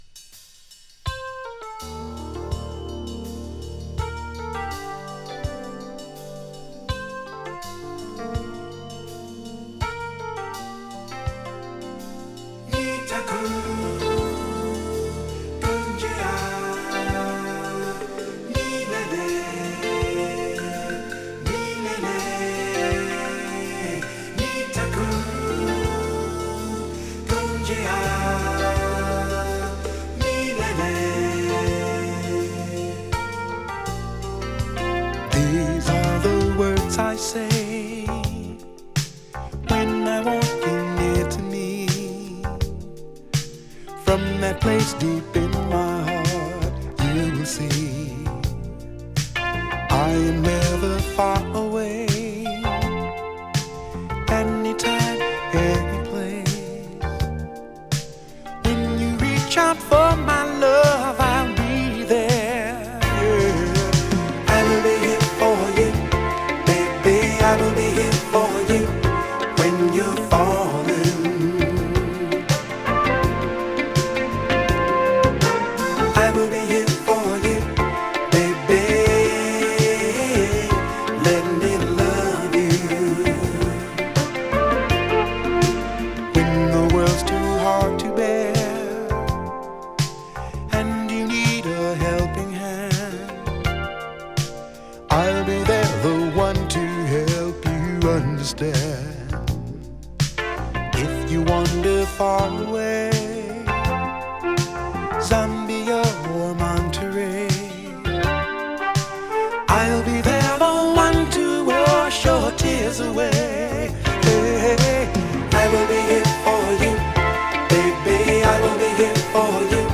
> AOR/FUSION
フュージョン風味の爽快グルーヴィー・ソウル
全編に散りばめられたメロウなテイストと軽やかなメロディ